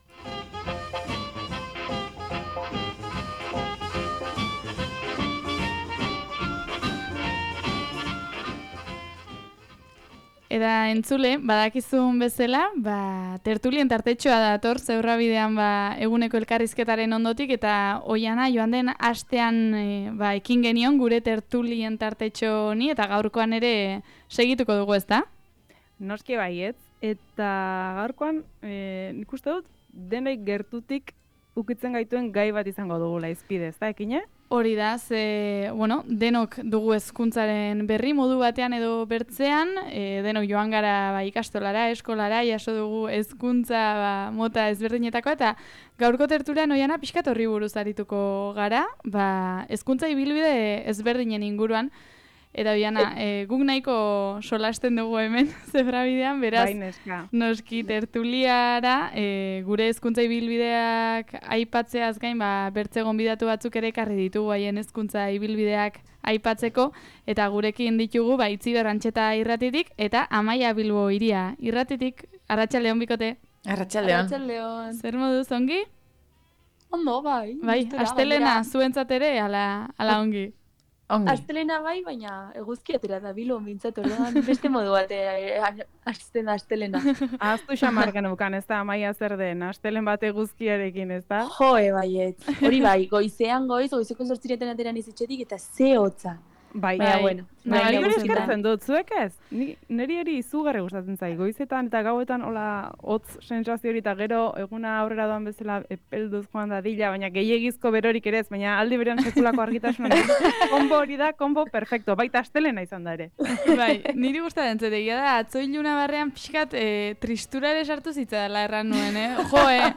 Gure tertulia